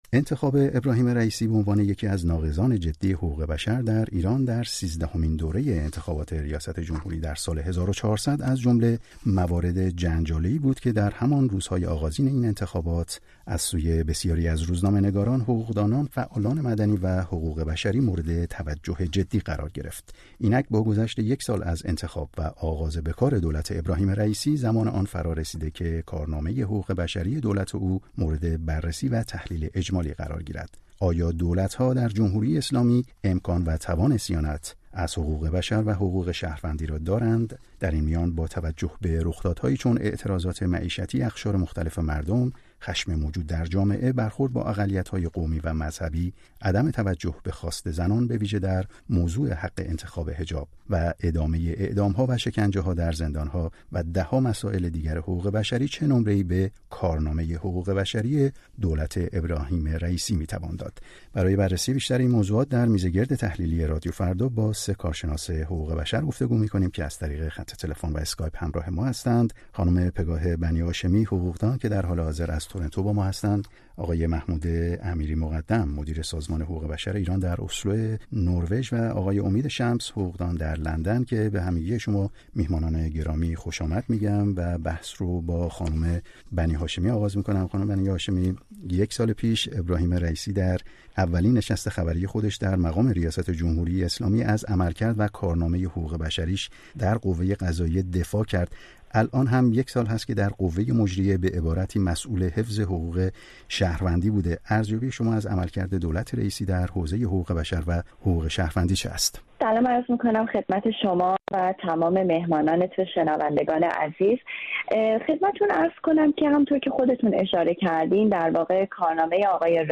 با گذشت ۳۶۵ روز از آغاز به کار دولت او، با حضور کارشناسانی در این زمینه، پرونده یک سال گذشته را با تمرکز بر موضوع حقوق بشر مرور کرده‌‌ایم.